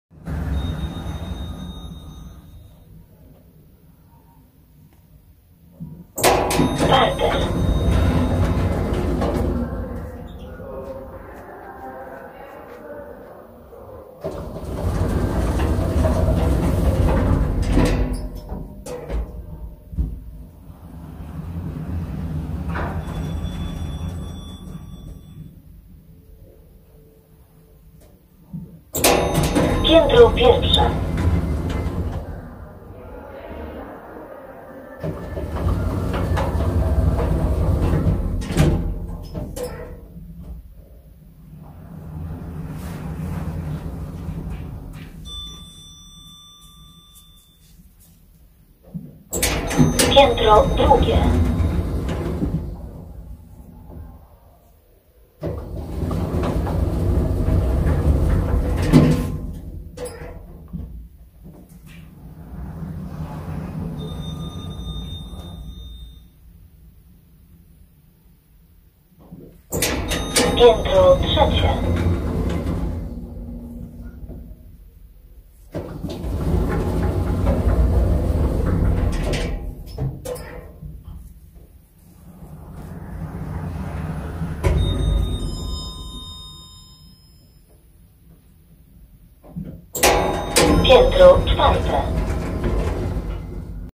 Elevator Sound Effect Free Download
Elevator